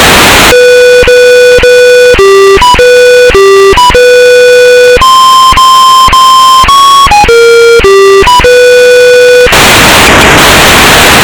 It is comprised of a melody "The Imperial March" (Star Wars) followed by 2 packets of 4800bps GFSK AX.25 G3RUH.